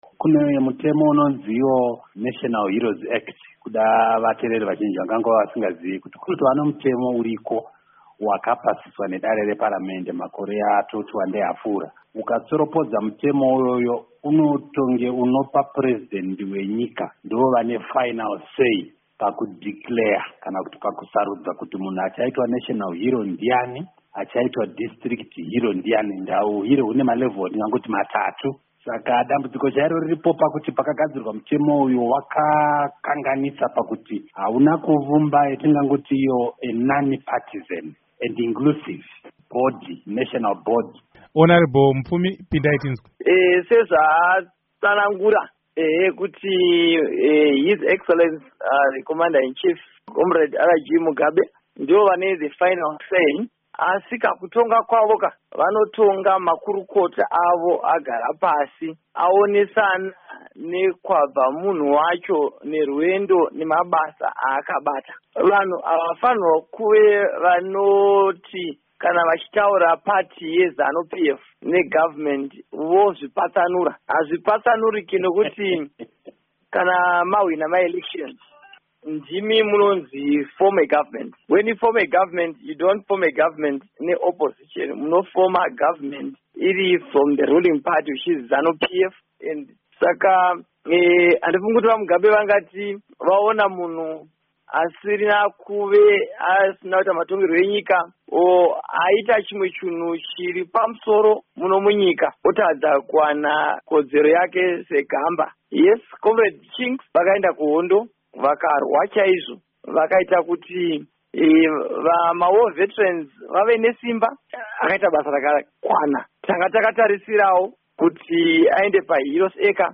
Hurukuro naVaObert Gutu naVaEsau Mupfumi